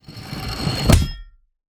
Звуки включают механические элементы работы устройства и фоновые атмосферные эффекты.
Падение гильотины, лезвие опускается с глухим стуком